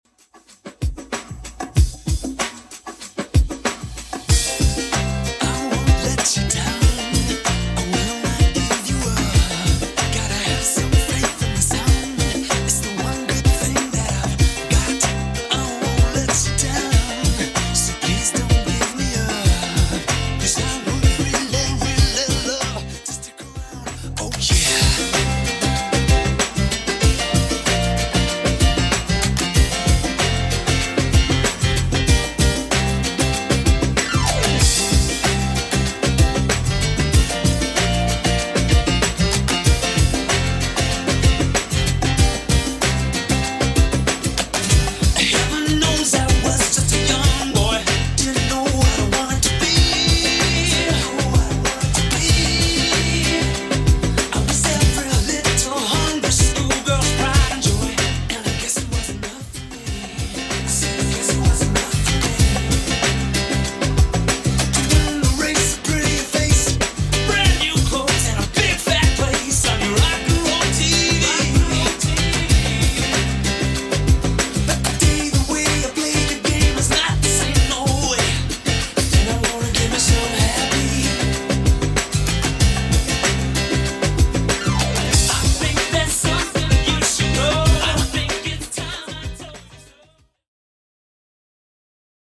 Genre: 80's
BPM: 112